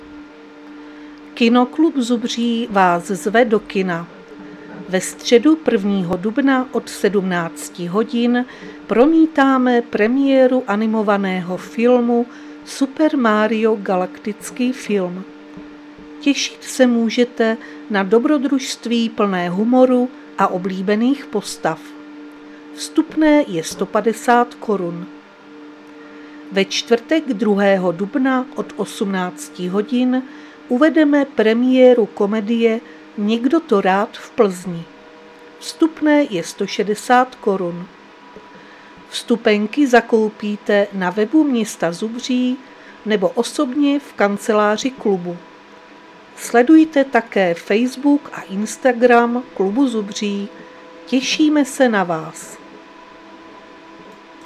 Záznam hlášení místního rozhlasu 1.4.2026
Zařazení: Rozhlas